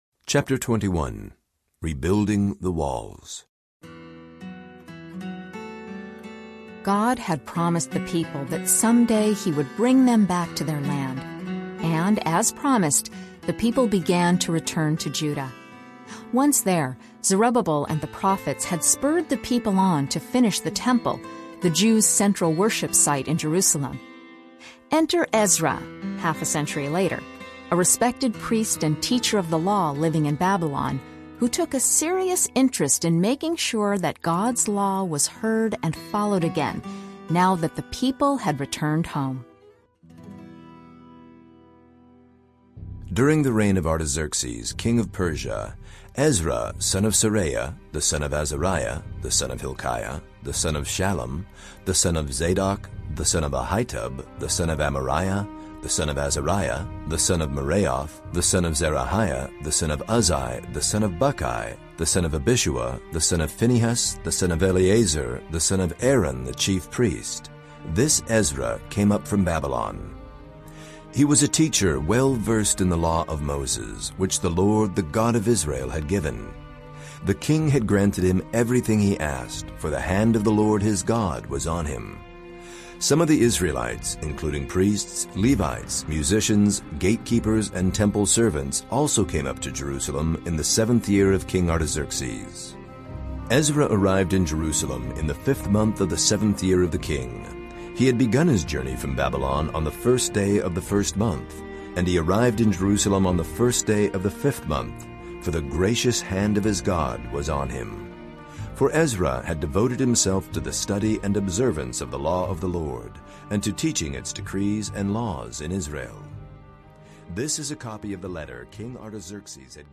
The Story Chapter 21 (NIV) Audiobook
The Story reveals the unfolding, grand narrative of the Scriptures. Using portions of the clear, accessible text of the NIV, this dramatized audio download of Chapter 21 — Rebuilding the Walls from The Story, NIV allows the stories, poems, and teachings of the Bible to come together in a single, compelling read.
.5 Hrs. – Unabridged